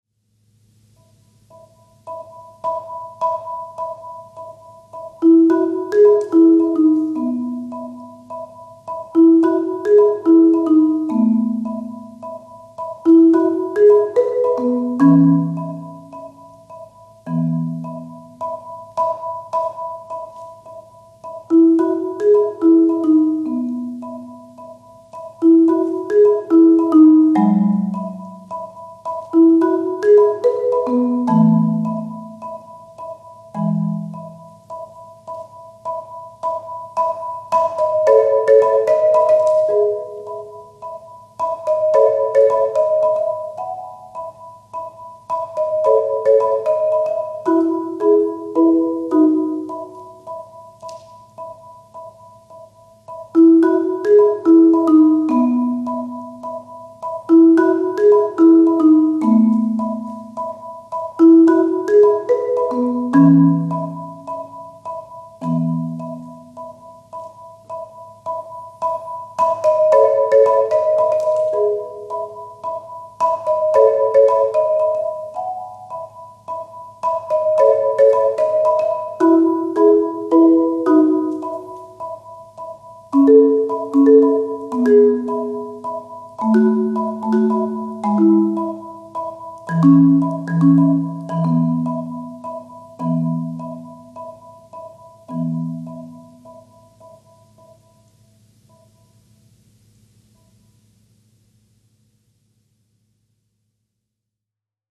Voicing: Mallet Method